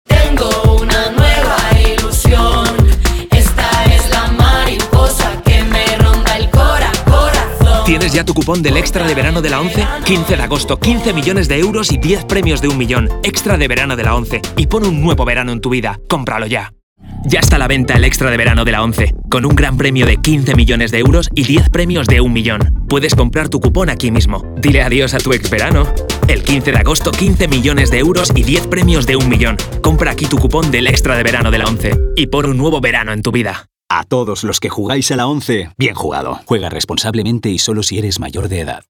Comercial, Natural, Urbana, Cool, Cálida
Comercial
He is characterized by having a special register that can lead to different timbres and tones, and having a voice with a fairly wide tonal range.